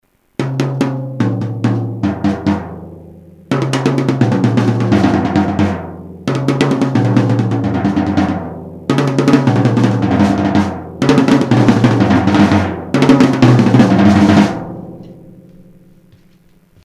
tom_fill_langzaam_naar_sneller.mp3